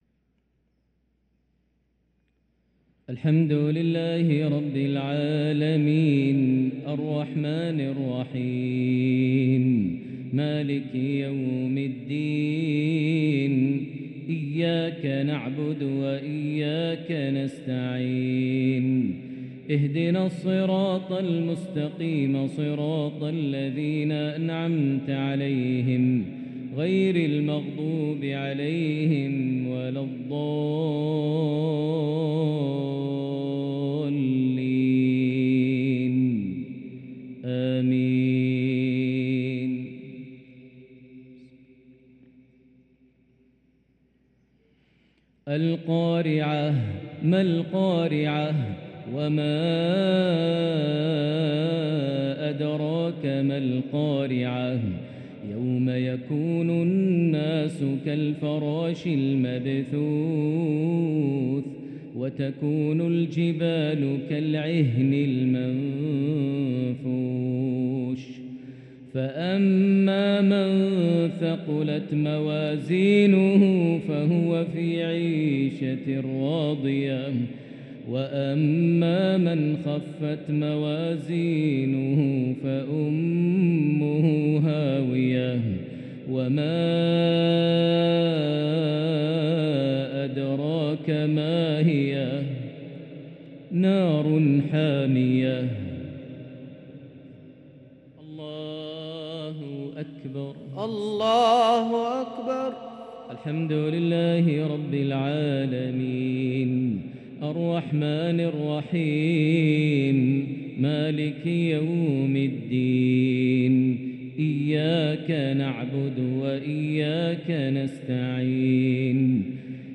Maghrib prayer from Surah al-Qari`ah & at-Takathur 3-6-2023 > 1444 H > Prayers - Maher Almuaiqly Recitations